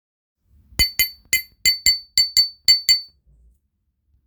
バナナベル
ジュンジュンなどに取り付けて演奏します。
シャープで腰のあるサウンドなので、いろいろ応用して使える鉄楽器です。
素材： 鉄